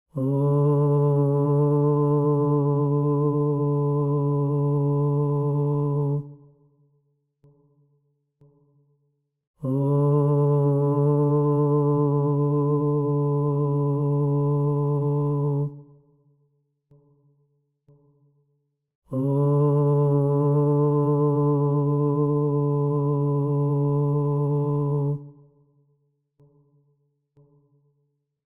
S5-02-3-repetitions-4-sec-silence.mp3